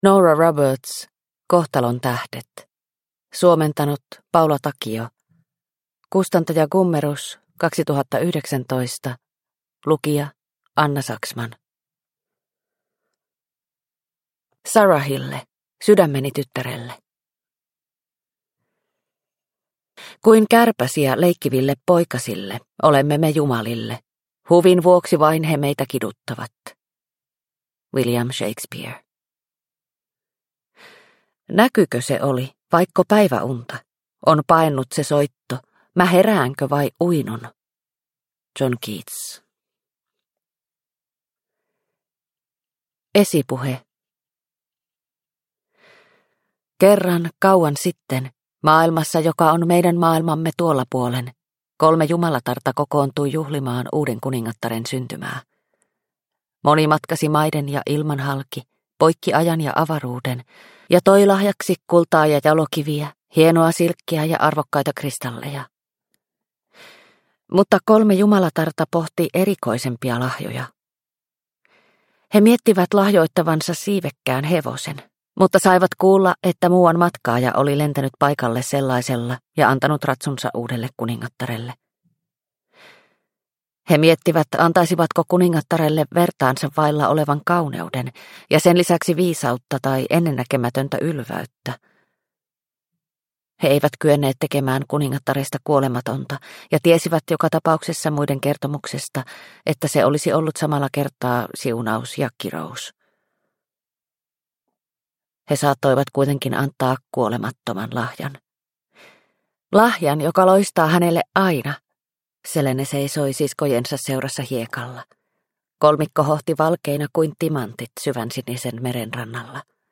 Kohtalon tähdet – Ljudbok – Laddas ner